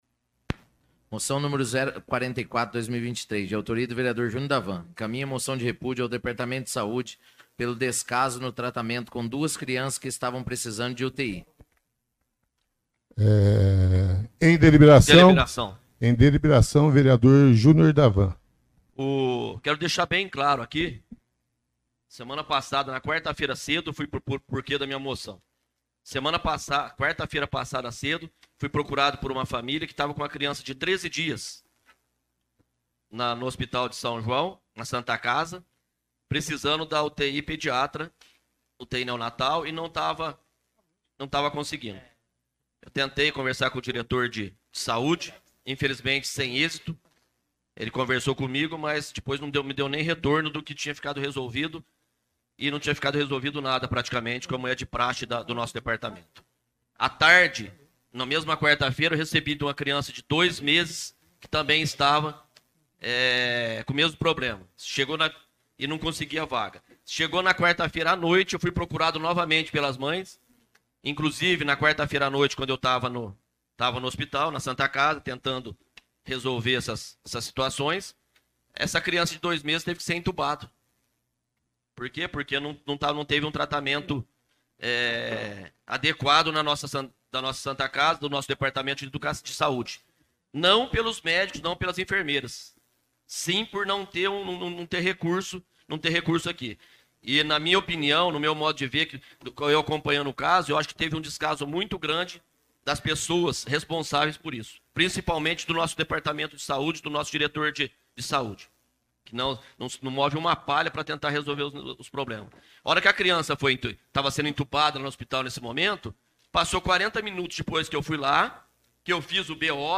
O caso de crianças que teriam tido problemas para achar vaga na UTI neonatal no sistema público de saúde causou uma discussão acalorada ontem (20), na sessão da Câmara Municipal de São João da Boa Vista.
Ouça o pronunciamento de Júnior da Van na íntegra: